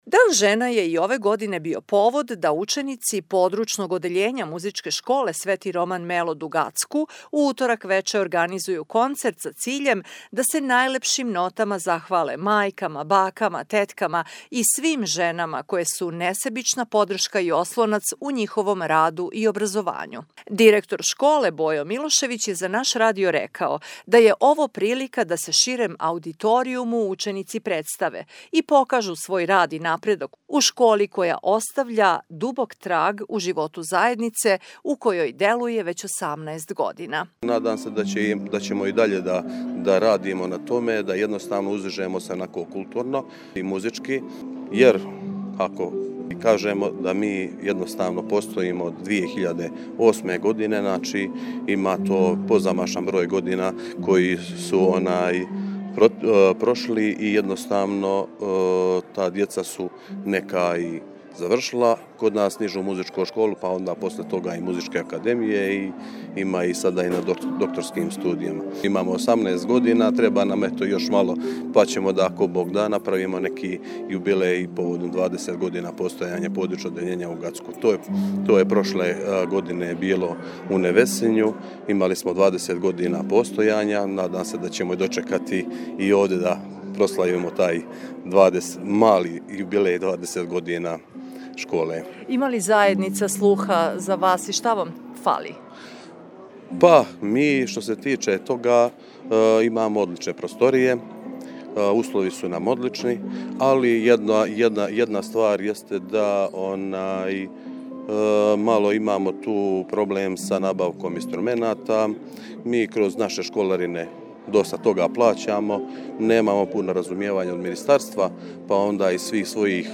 Дамама Гацка на дар - ученици Музичке школе приредили концерт поводом 8. марта - Радио Гацко
koncert-muzicke-skole-8.-mart.mp3